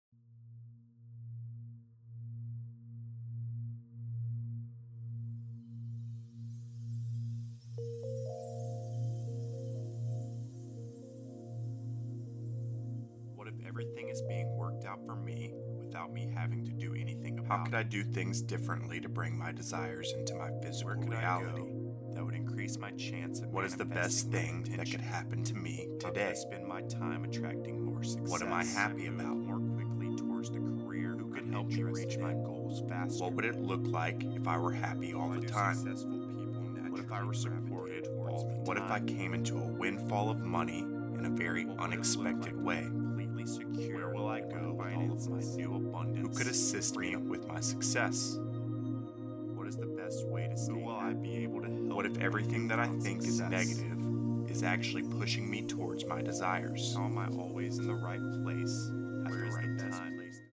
This meditation track is produced with dual-induction sound technology for over 500 subliminal impressions that cue the subconscious mind to search for success.
Musical composition by Christopher Lloyd Clarke.